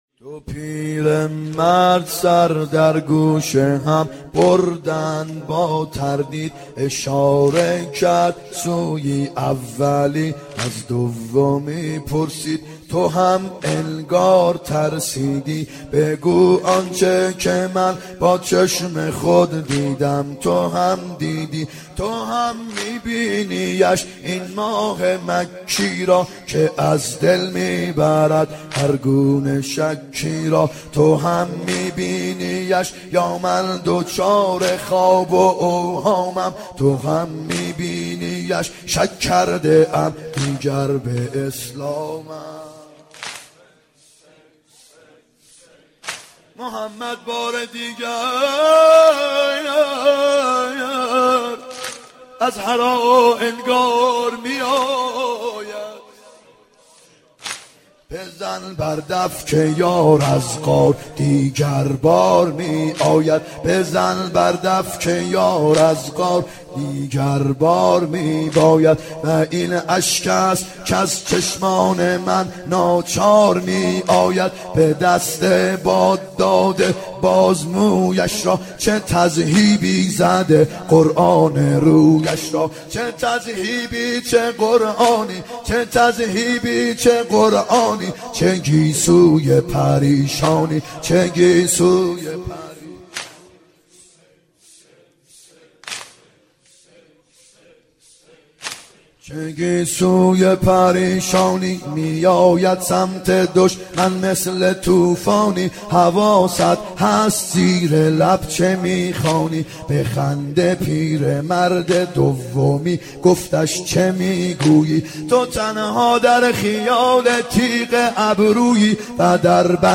محرم 90 ( هیأت یامهدی عج)